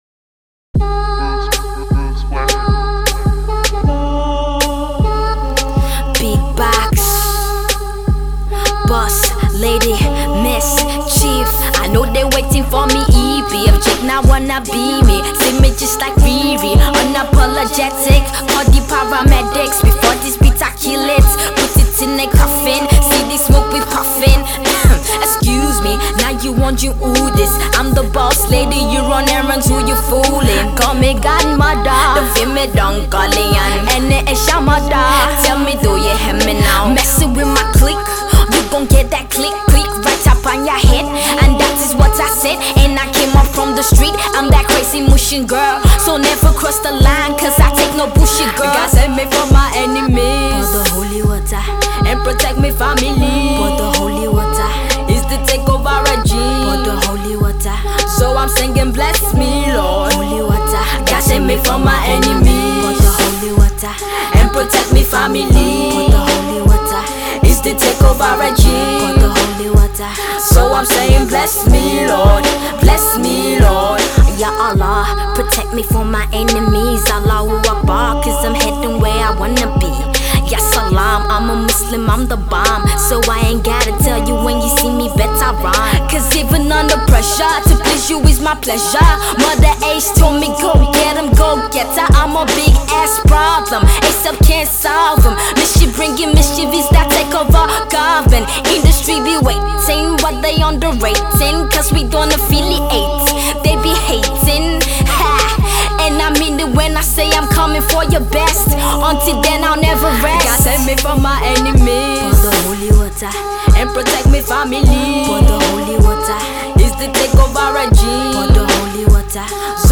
freestyle